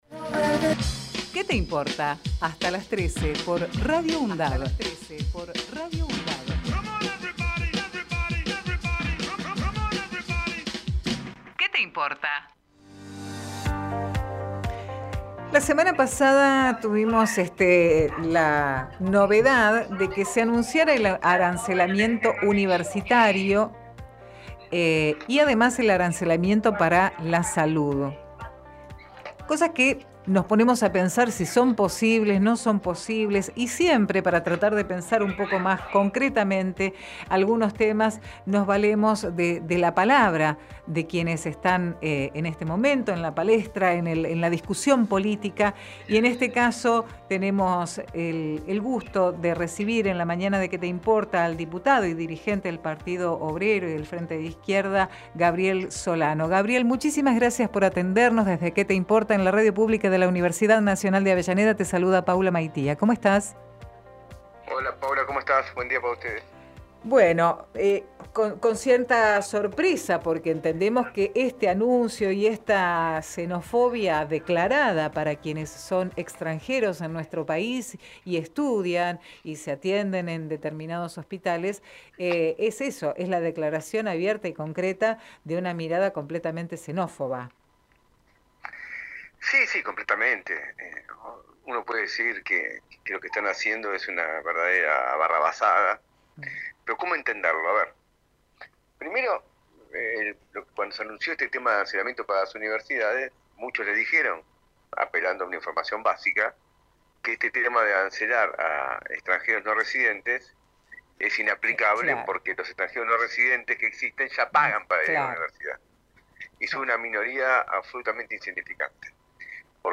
QUÉ TE IMPORTA-Gabriel Solano Texto de la nota: Compartimos con ustedes la entrevista realizada en "Qué te importa?!" a Gabriel Solano, Diputado del Partido Obrero, Frente de Izquierda.